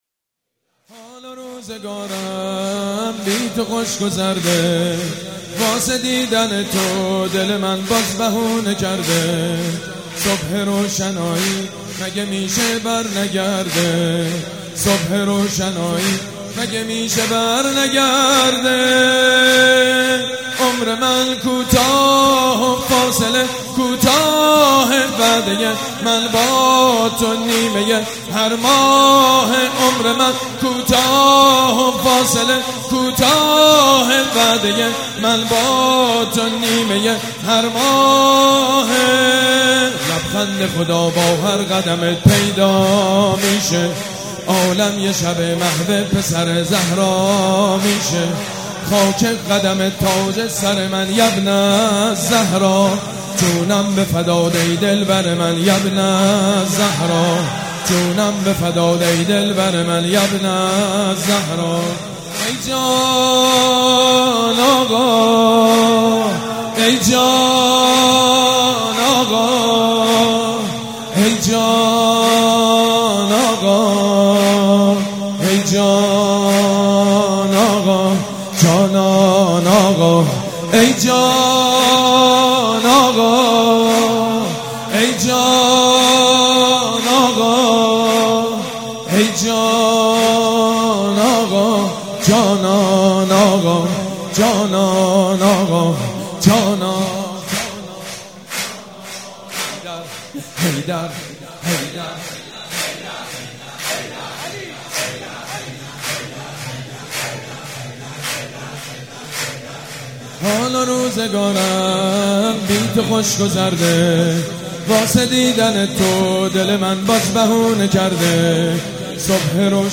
سرود: حال و روزگارم بی تو خشک و سرده